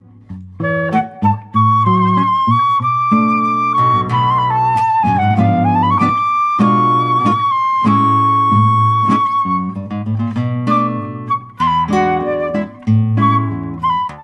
MHydEkNAlMr_tango-guitarra.WAV